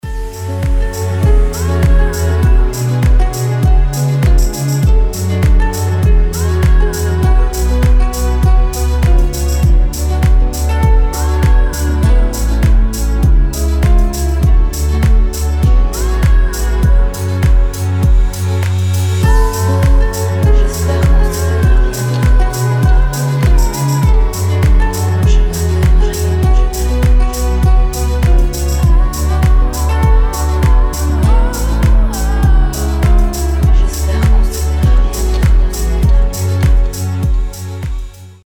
Ностальгическая мелодия - рингтон